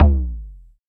LOGTOM LO1P.wav